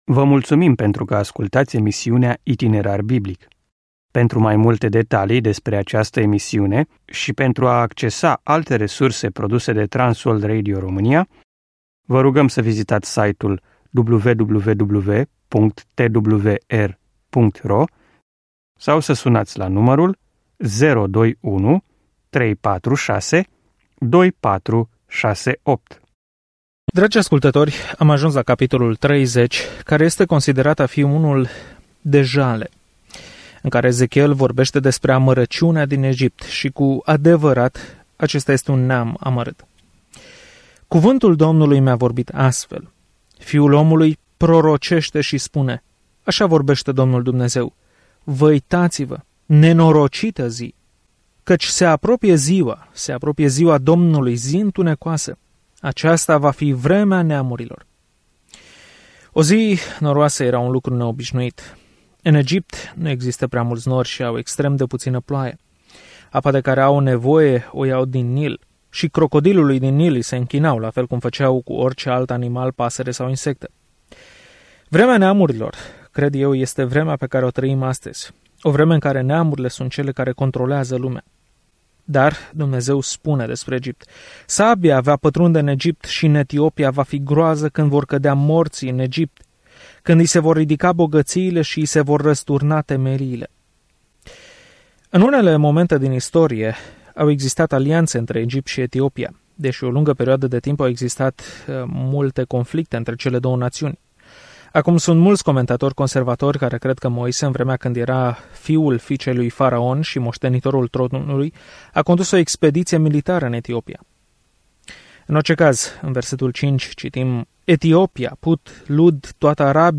Scriptura Ezechiel 30 Ezechiel 31 Ziua 15 Începe acest plan Ziua 17 Despre acest plan Oamenii nu au vrut să asculte cuvintele de avertizare ale lui Ezechiel de a se întoarce la Dumnezeu, așa că, în schimb, el a jucat pildele apocaliptice și le-a străpuns inimile oamenilor. Călătoriți zilnic prin Ezechiel în timp ce ascultați studiul audio și citiți versete selectate din Cuvântul lui Dumnezeu.